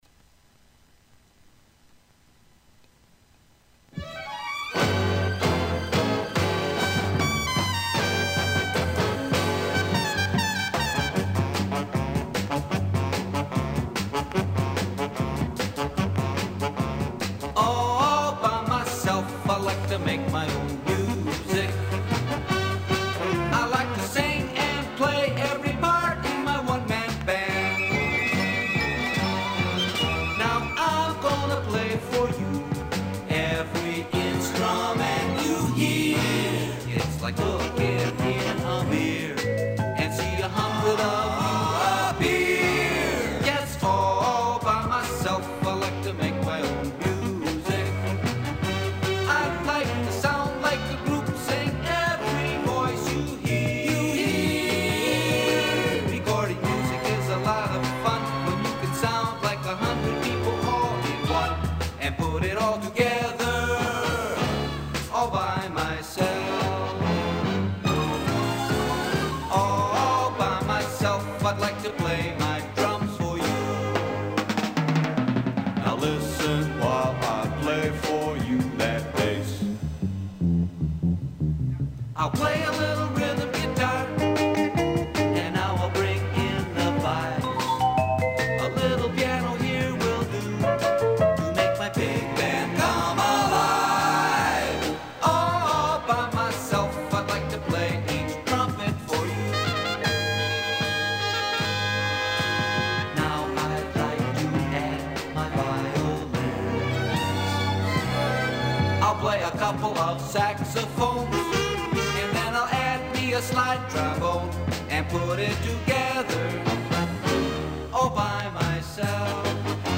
Through the medium of multitrack recording